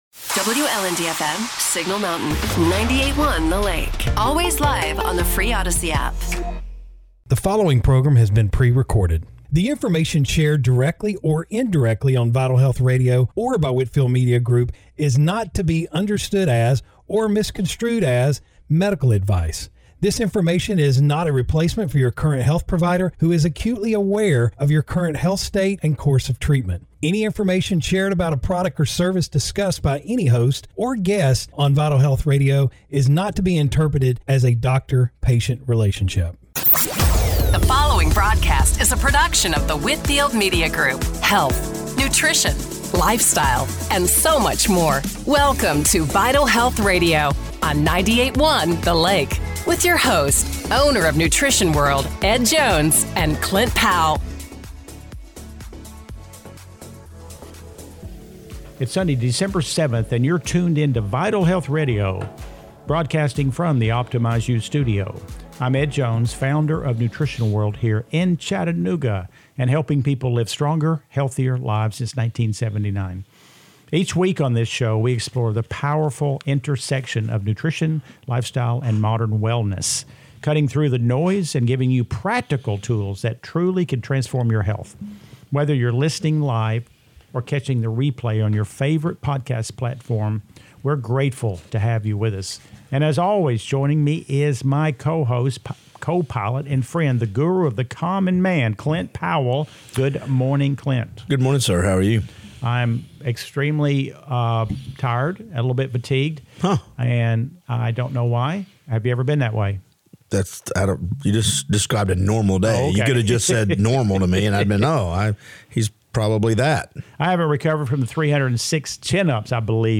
Radio Show / Podcast – December 7, 2025 - Vital Health Radio
Broadcasting from the Optimize U Studio